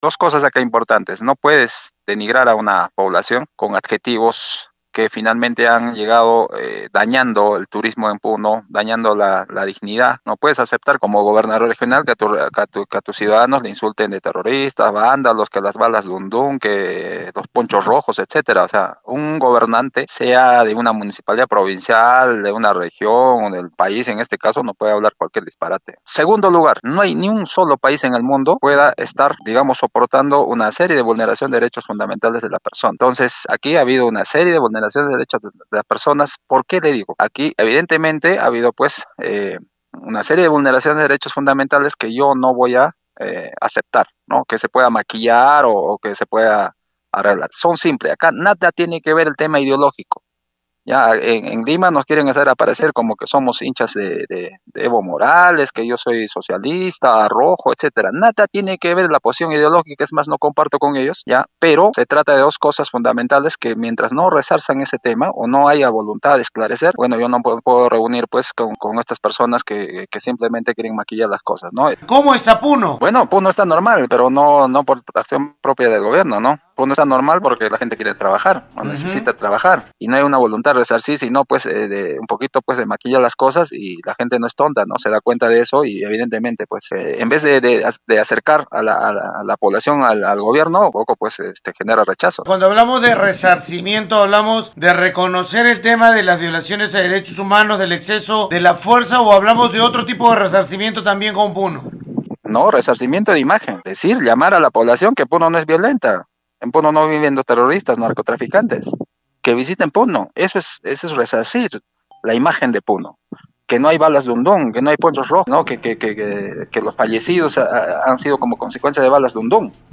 gobernador-de-puno.mp3